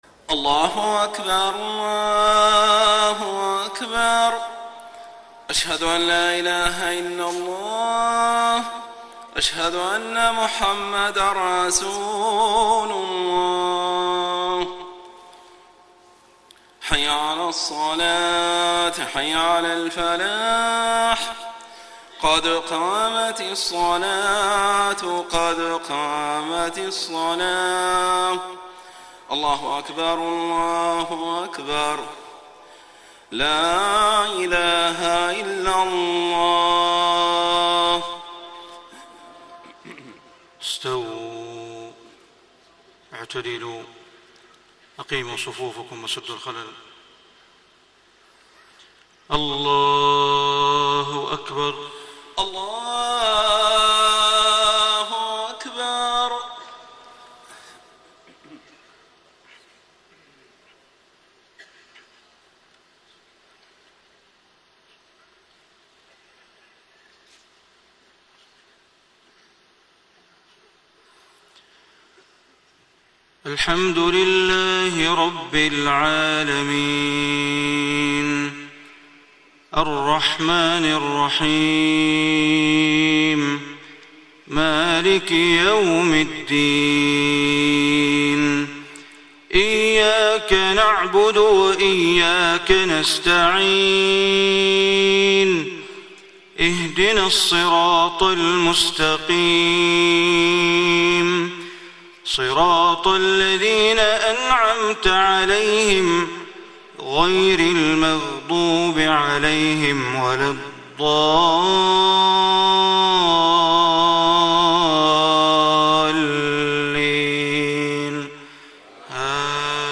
صلاة الفجر 2 - 1 - 1435هـ من سورة الأنبياء > 1435 🕋 > الفروض - تلاوات الحرمين